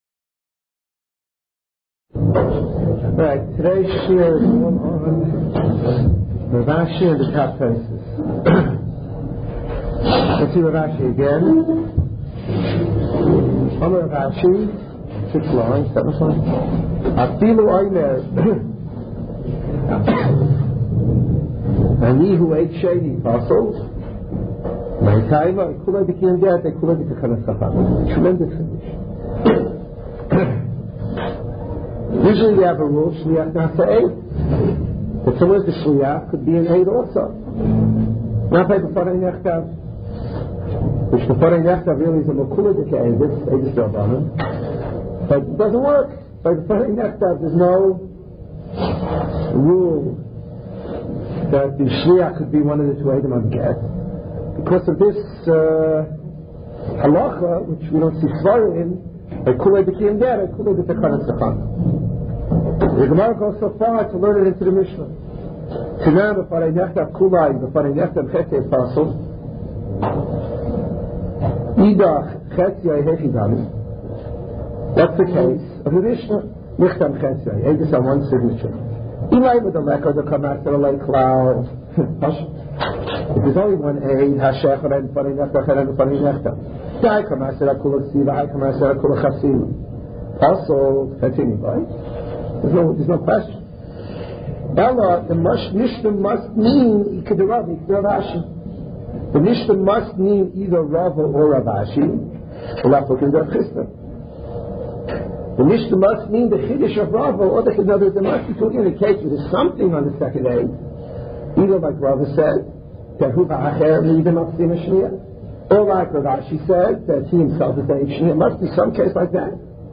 Shiurim and speeches on Gemarah, Halachah, Hashkofo and other topics, in mp3 format